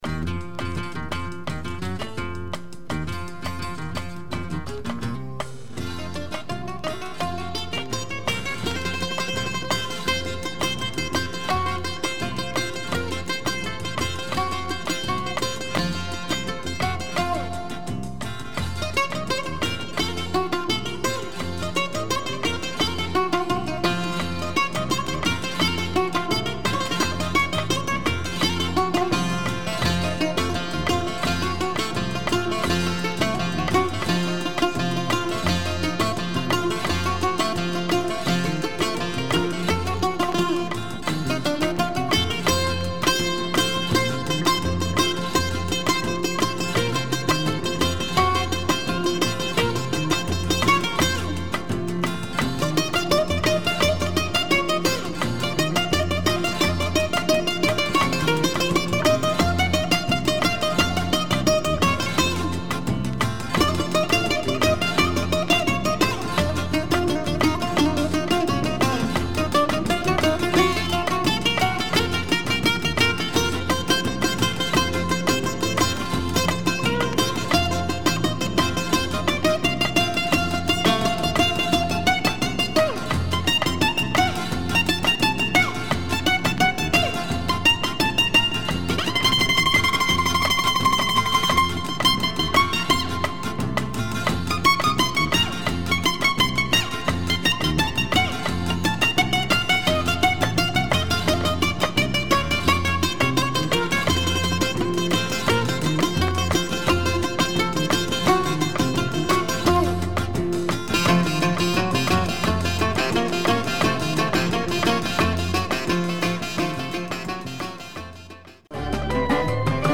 70's Greek music
mizrahi